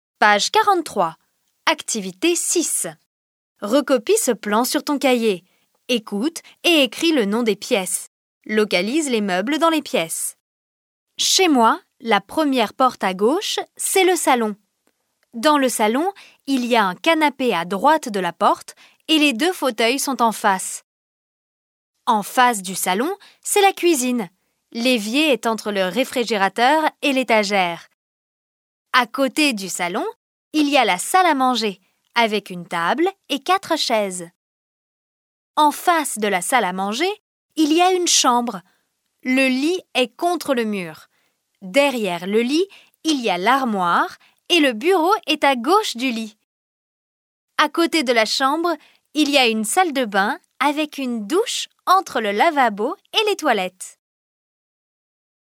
Аудіювання